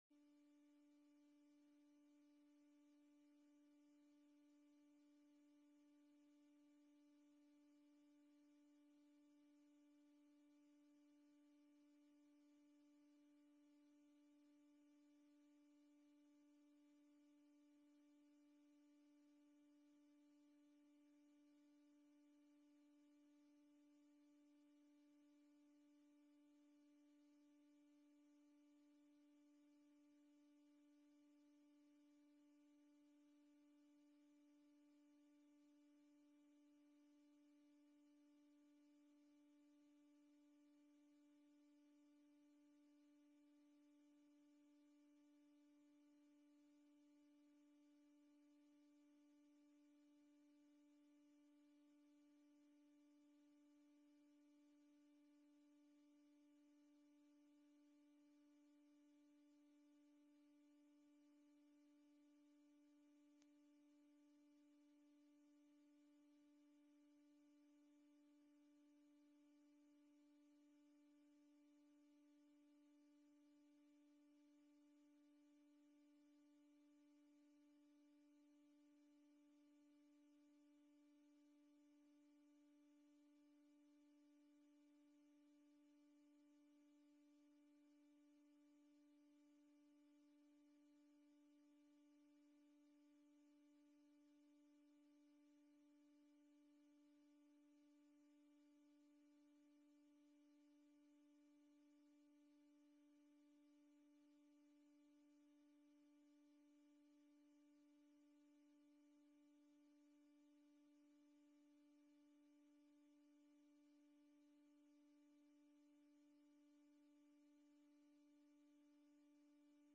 Agenda Nieuwegein - Raadsvergadering donderdag 4 juni 2020 20:00 - 23:00 - iBabs Publieksportaal
Online, te volgen via livestreaming
Voorzitter Frans Backhuijs